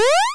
salto1.wav